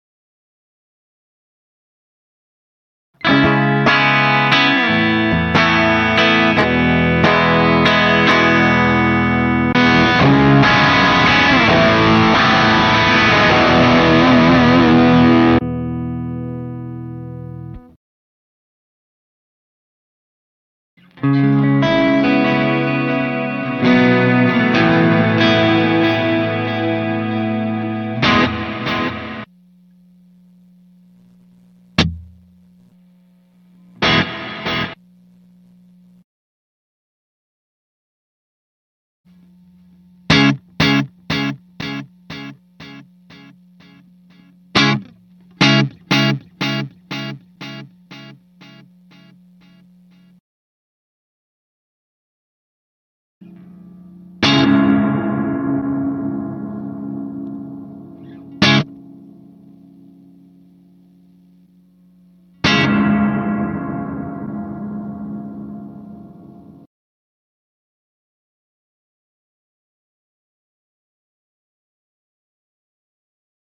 VALETON GP 5 PRESET SWITCHING & Sound Effects Free Download